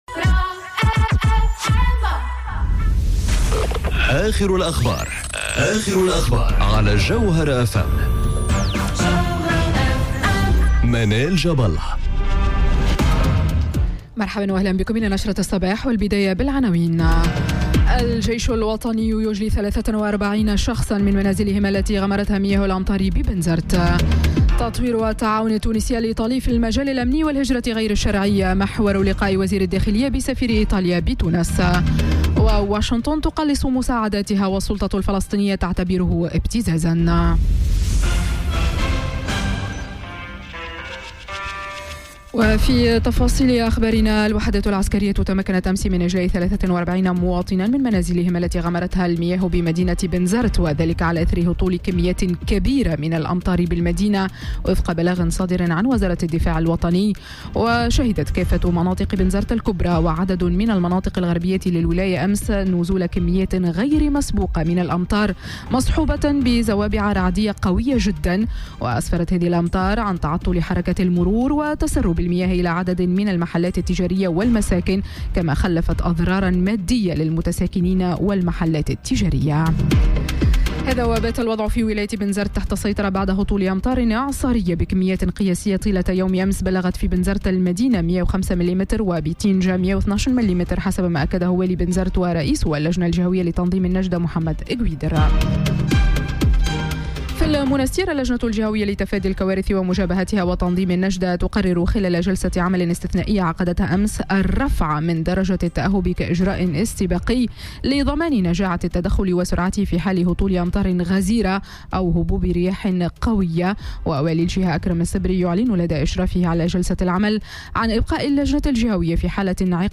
نشرة أخبار السابعة صباحا ليوم السبت 25 أوت 2018